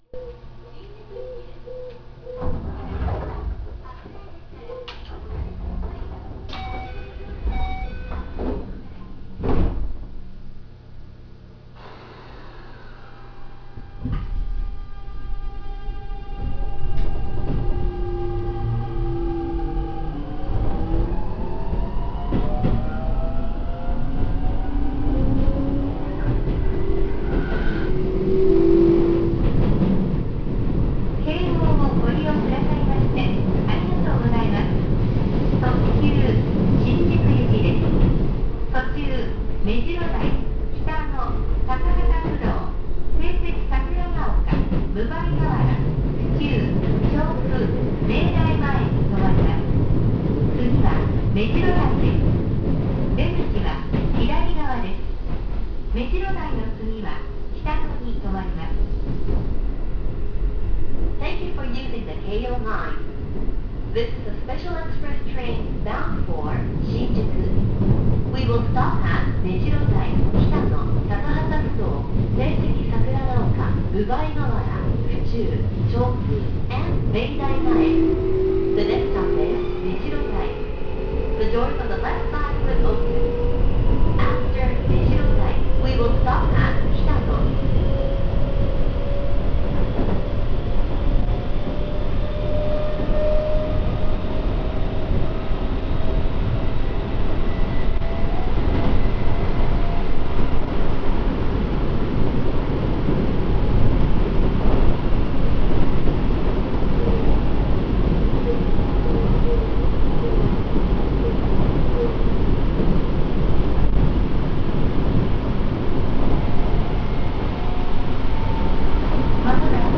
・8000系走行音
【高尾線】高尾〜めじろ台（3分7秒：0.99MB）
標準的な日立GTOで、とても重厚な音を出してくれます。近年、自動放送も設置されました。